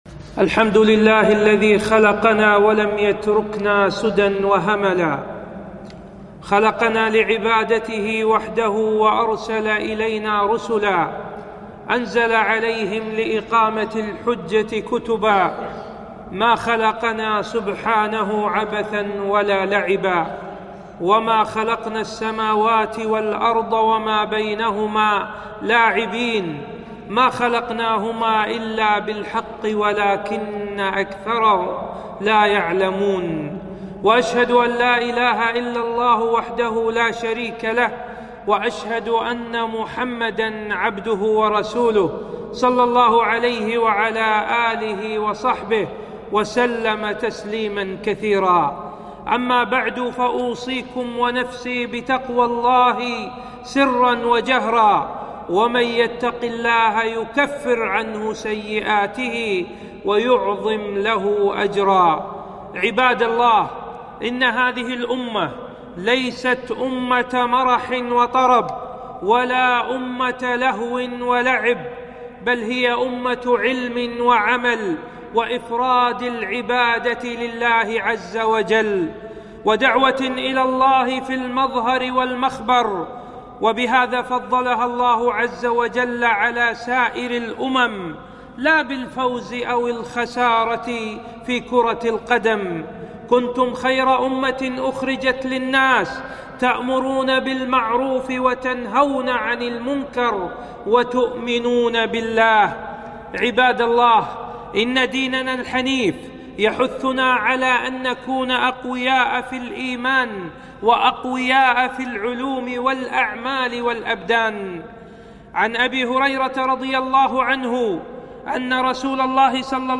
خطبة - حكم كأس العالم وتوجيهات حوله